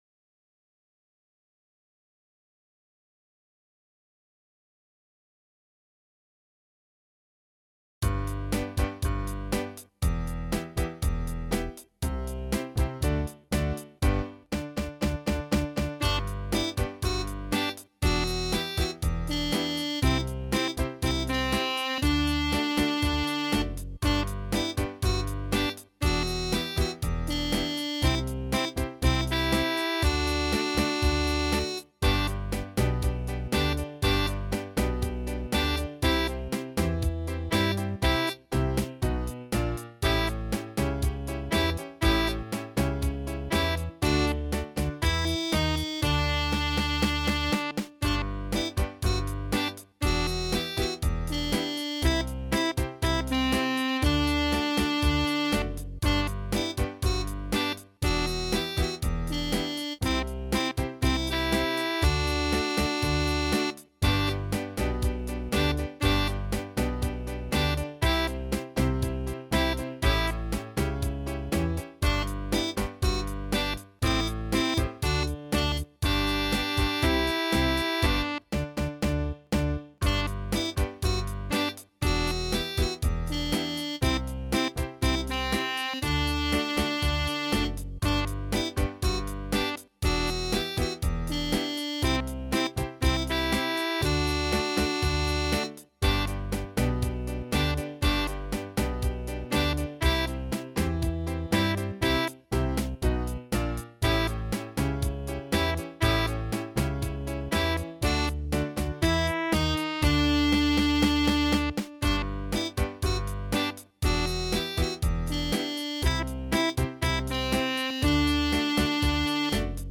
Rock style compositions
Bass Guitar/Tuba
Keyboard
Guitar
Drum Kit